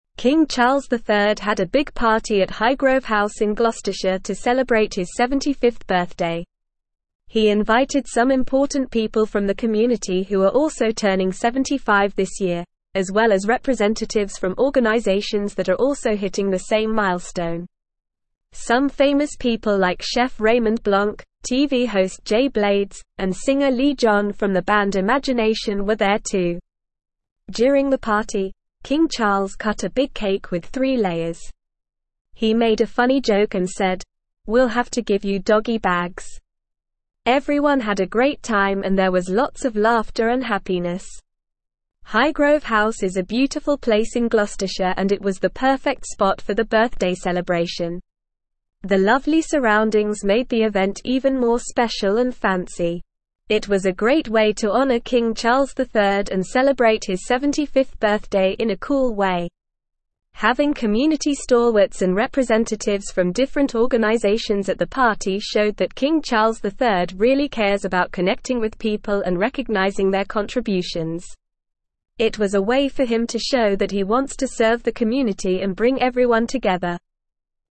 Normal
English-Newsroom-Upper-Intermediate-NORMAL-Reading-King-Charles-III-Celebrates-75th-Birthday-with-Joyful-Party.mp3